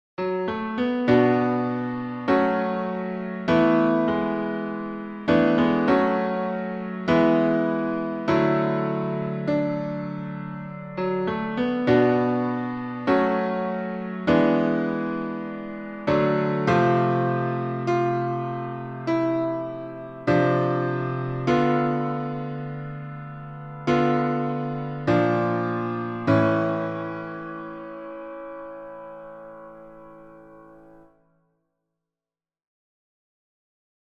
Key written in: A Major
How many parts: 4
Type: Barbershop
All Parts mix: